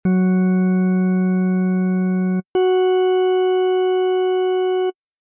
This is another very bright overtone, so you can adjust the volume of it according to your taste.